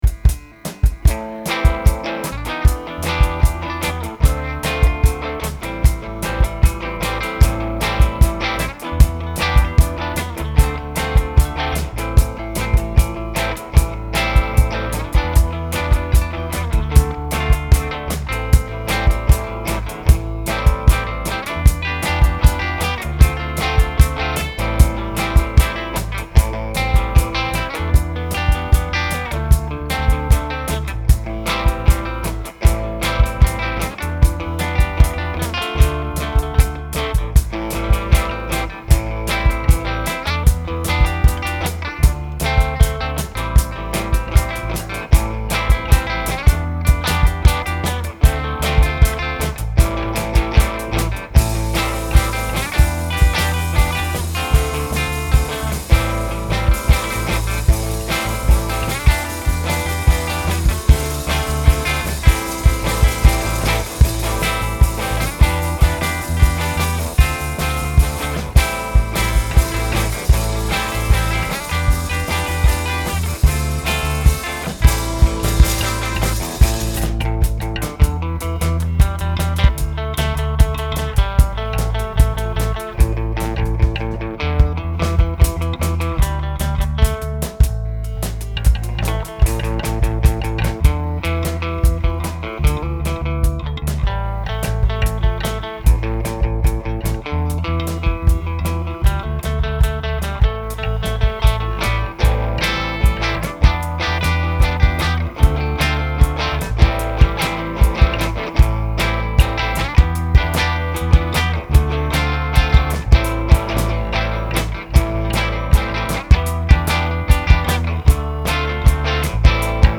04MiracleDrug(twodrums).mp3